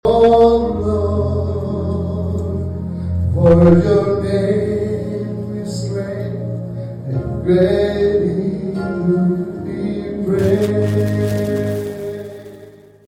Sunday’s special service included prayers, a recognition from Mid-America Nazarene University, messages from prior pastors and music.